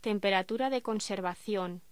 Locución: Temperatura de conservación